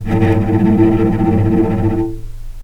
vc_trm-A2-pp.aif